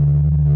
PwrEngAmb2.wav